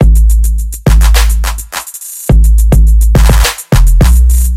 双拍子陷阱节奏
描述：你的典型的陷阱节拍，因为那已经被做了。
Tag: 105 bpm Trap Loops Drum Loops 787.67 KB wav Key : A